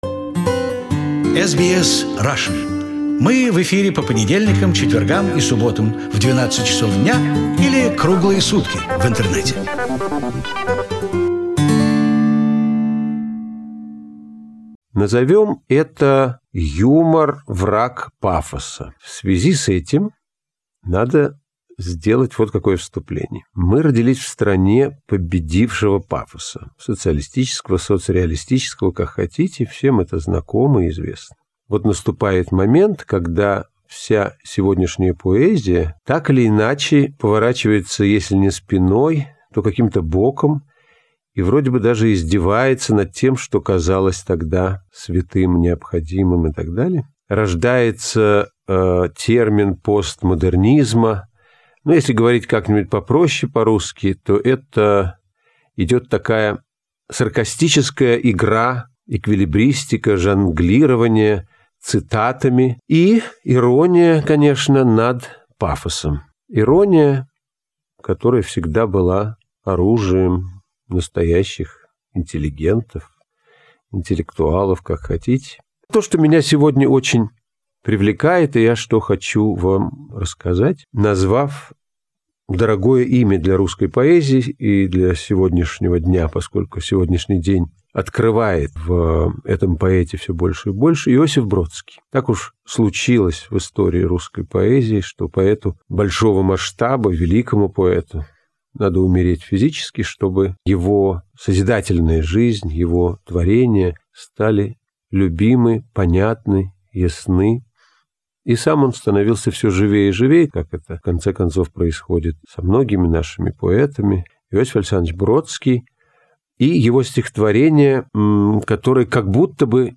Among them was leading actor of the famous Moscow Taganka Theatre, a movie star, director and writer Veniamin Smekhov. His concerts were a big success in Australia, and we asked him to record some of his material for radio. Fortunately the recordings survived and we are happy to preserve them on our web archives In this recording he reads a satirical parody by Joseph Brodsky Dedicated to Chekhov.